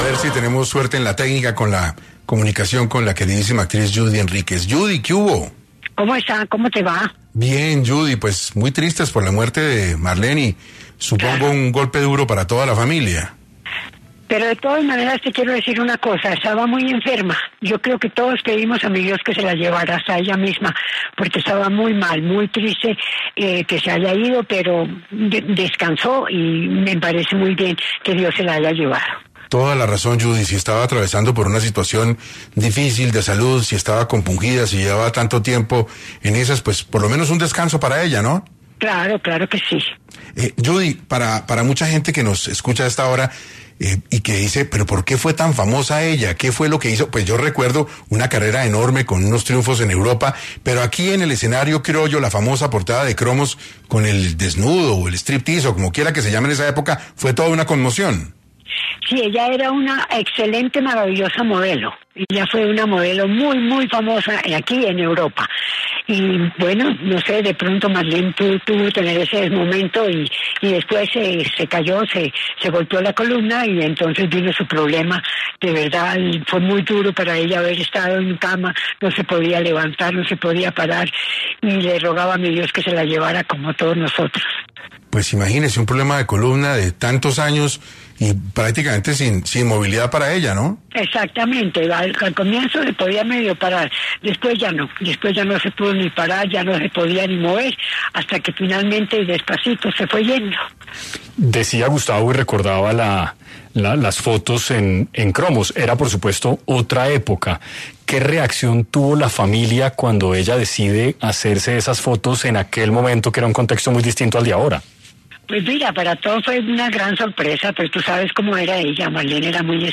La actriz, Judy Henríquez, pasó por los micrófonos de 6AM para hablar de su hermana Marlene Henríquez Lux.